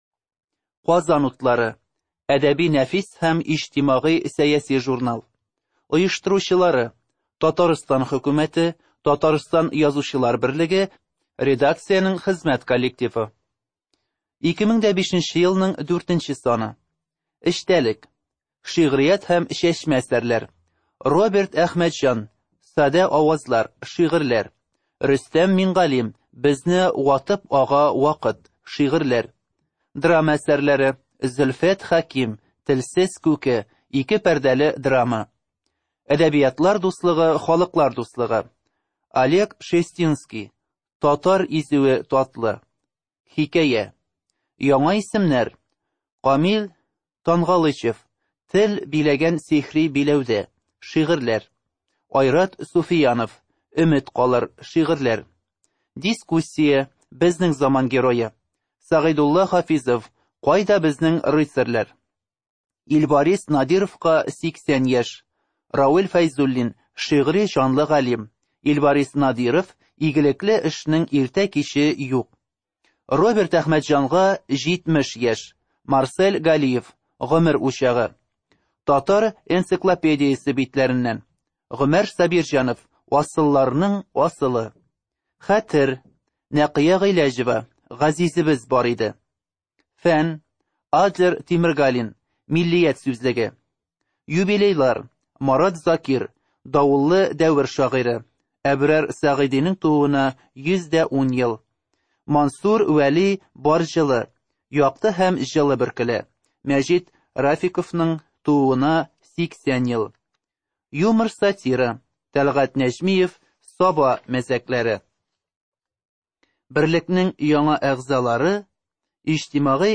Жанркниги на языках народов России
Студия звукозаписиТатарская республиканская специальная библиотека для слепых и слабовидящих